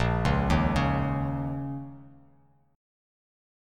A#mbb5 chord